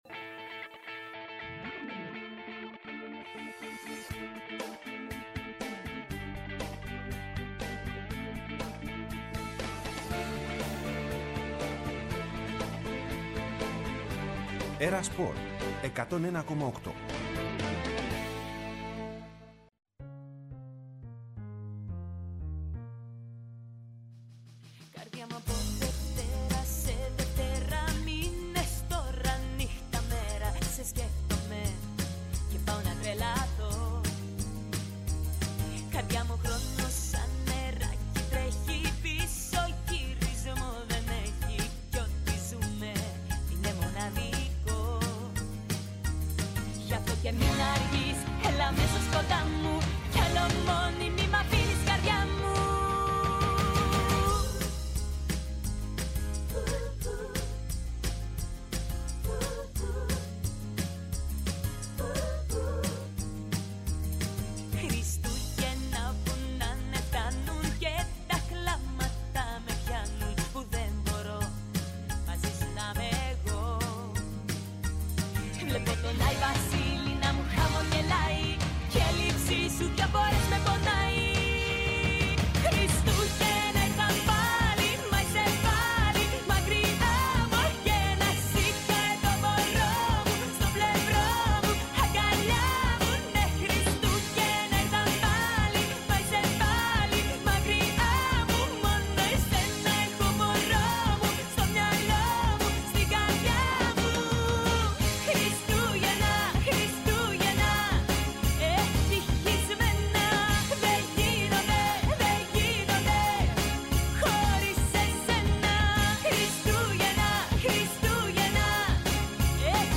το πρωινό των Χριστουγέννων, σε μια δίωρη χαλαρή εκπομπή, γεμάτη ευχές κ σπορ.